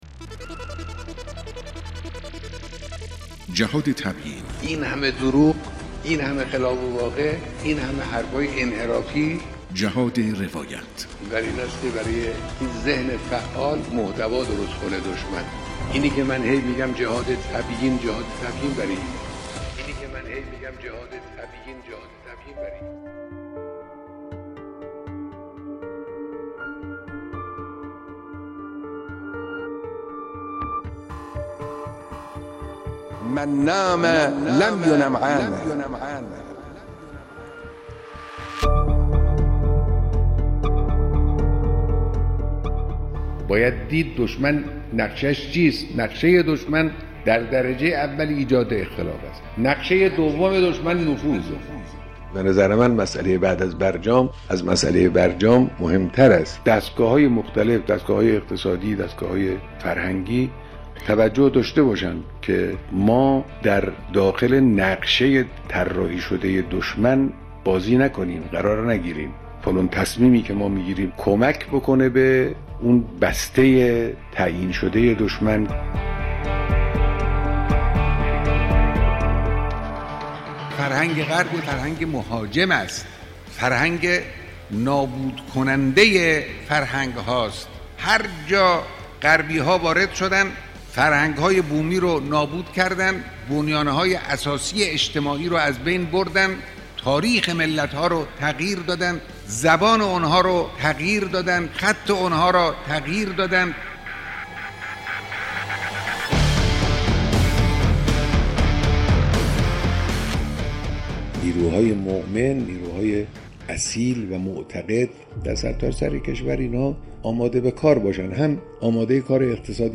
بیانات رهبری در مورد نقشه دشمن در درجه اول ایجاد اختلاف و در گام بعد نفوذ است...